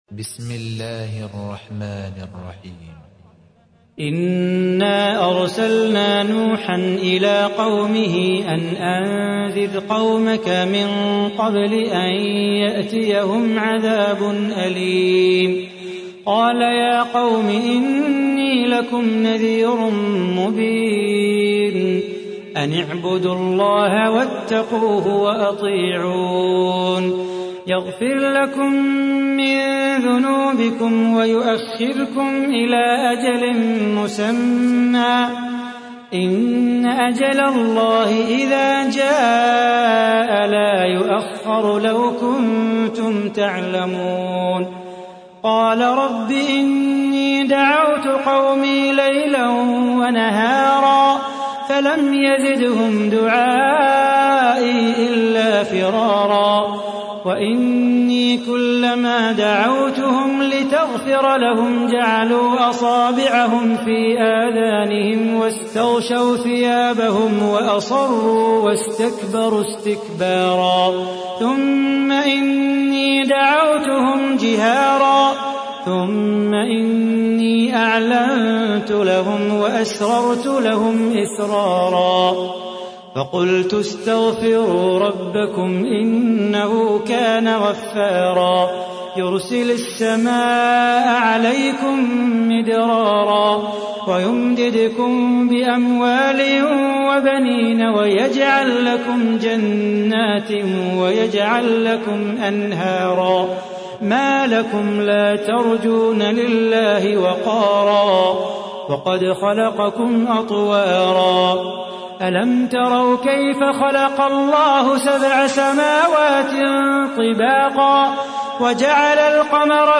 تحميل : 71. سورة نوح / القارئ صلاح بو خاطر / القرآن الكريم / موقع يا حسين